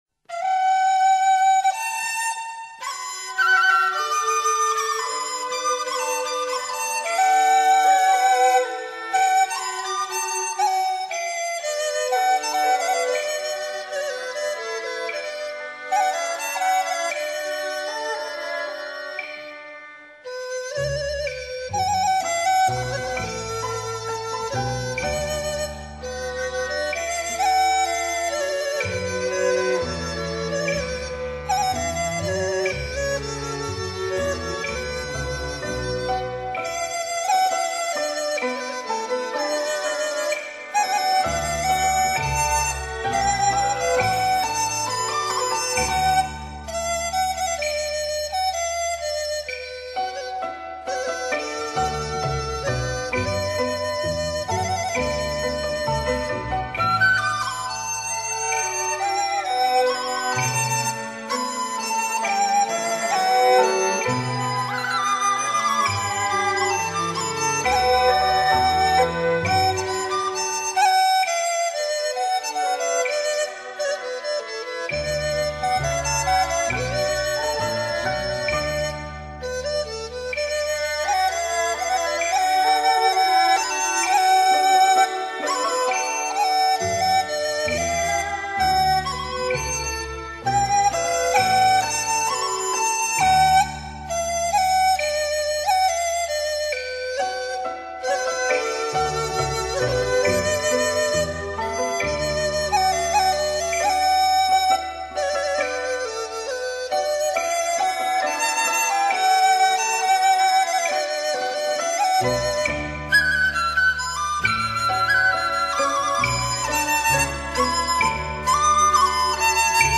山东传统乐曲